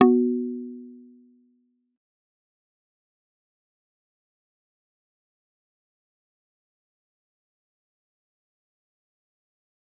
G_Kalimba-B3-mf.wav